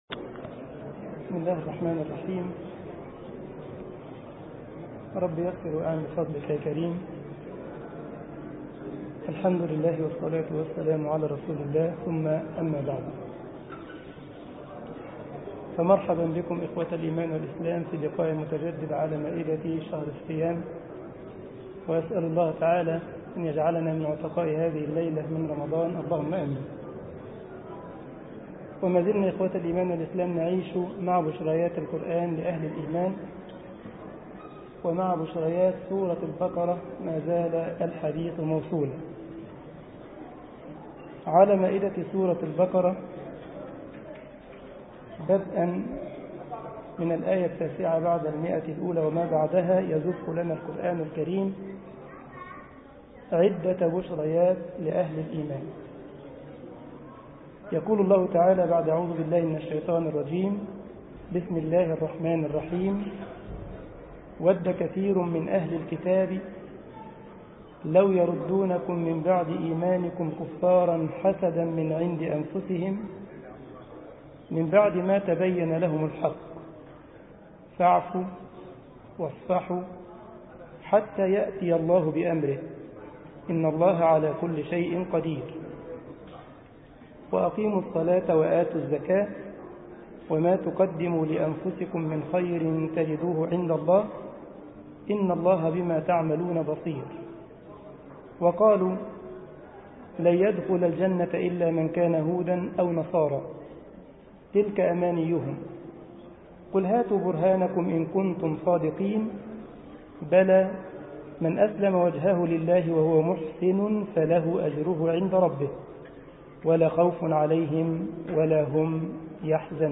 مسجد الجمعية الإسلامية بالسارلند ـ ألمانيا درس 04 رمضان 1433 هـ